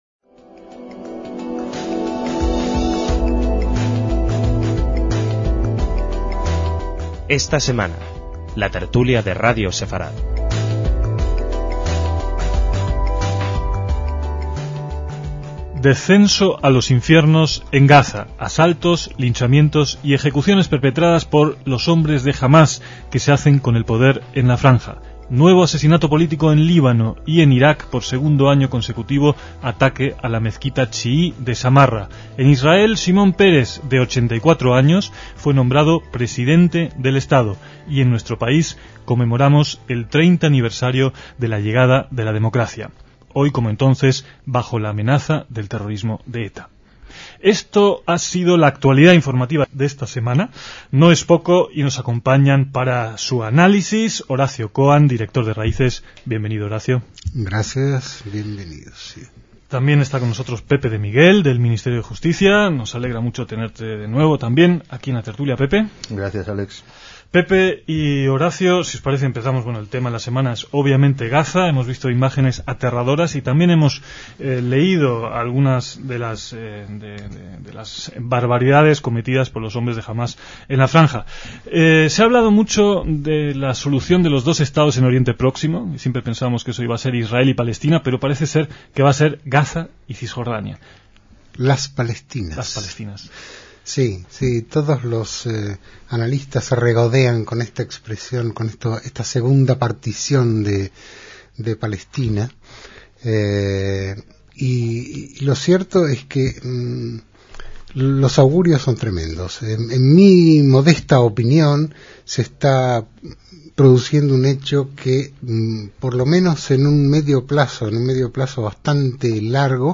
DECÍAMOS AYER (16/6/2007) - Gaza y España son los temas tratados en esta tertulia de 2007